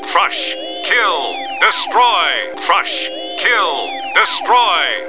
• Typ: Durchgeknallter Arakyd Mark V Battle Droid
Sprach-Sample
DEV-S-T8R ist glücklich